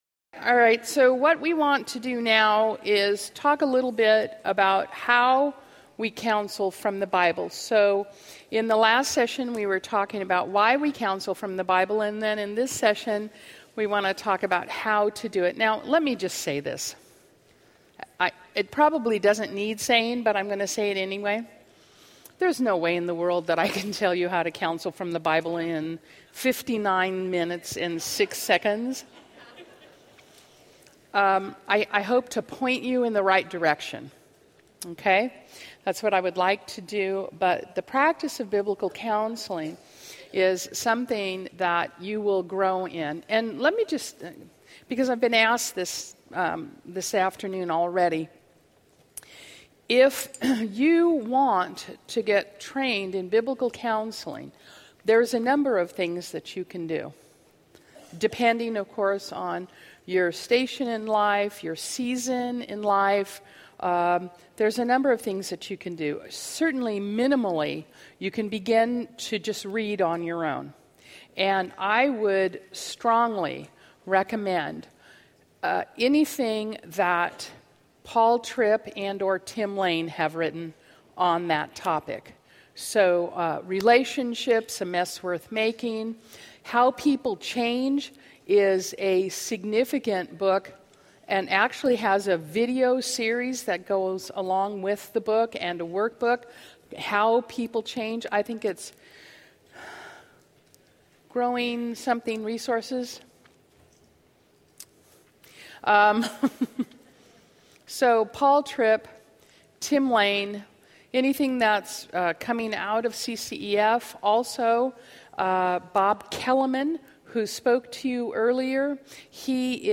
The Practice: How We Counsel from the Bible | Revive '13 | Events | Revive Our Hearts